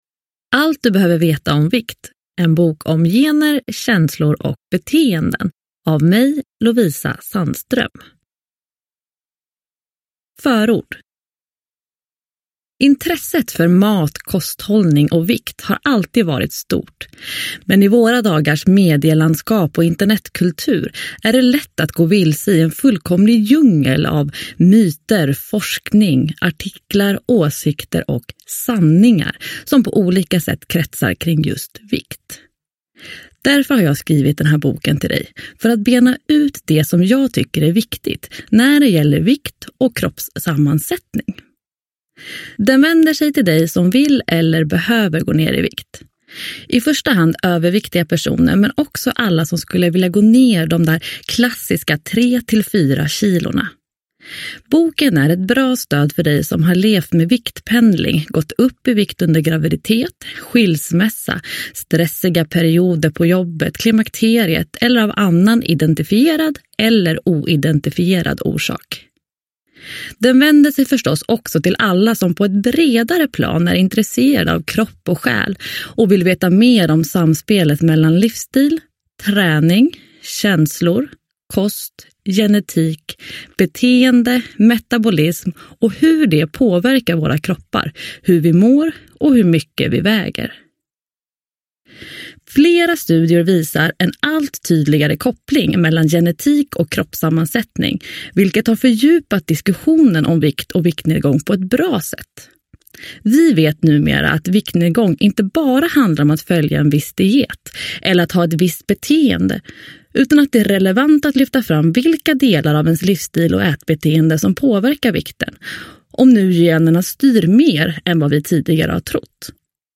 Allt du behöver veta om vikt : en bok om gener, känslor och beteenden – Ljudbok – Laddas ner